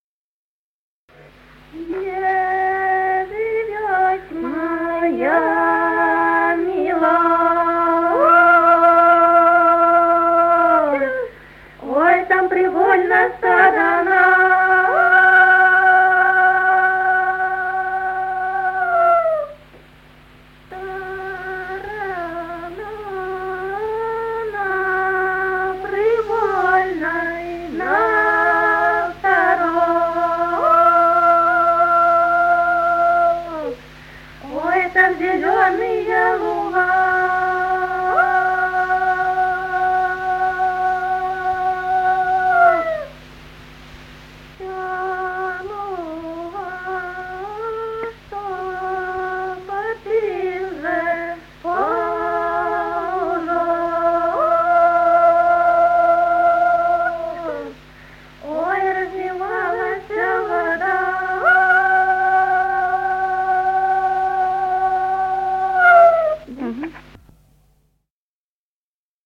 Народные песни Стародубского района «Где живёть моя милая», весняная девичья.